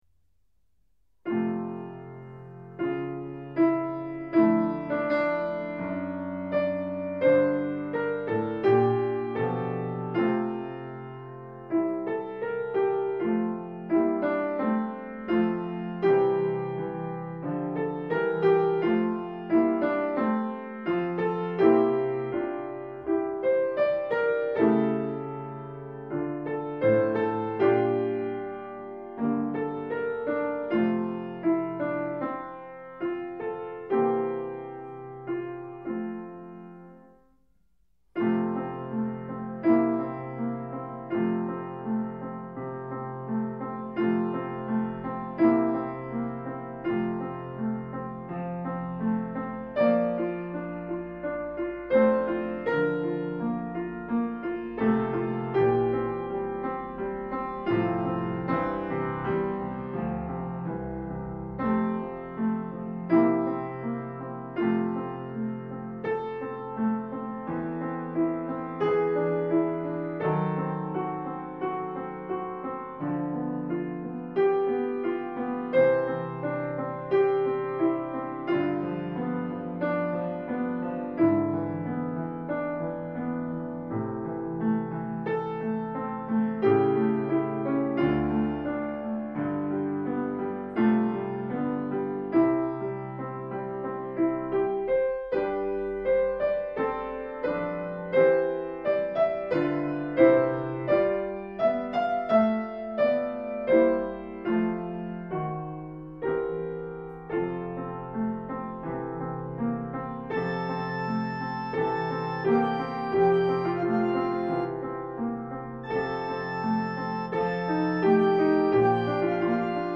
A.A. 24/25 Canto Corale